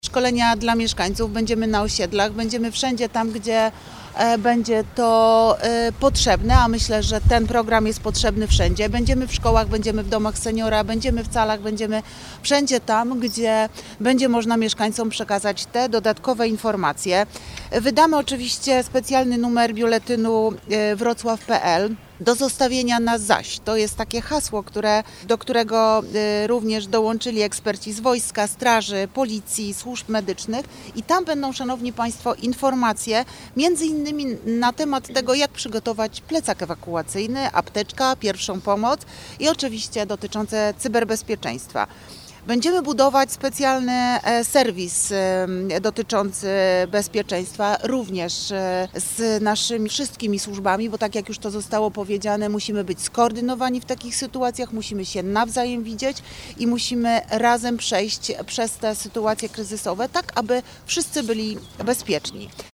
– Program ma dać mieszkańcom realne narzędzia do radzenia sobie z zagrożeniami – dodaje Renata Granowska.